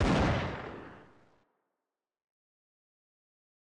🌲 / midnight_guns mguns mgpak0.pk3dir sound weapon magnum
fire_dist.ogg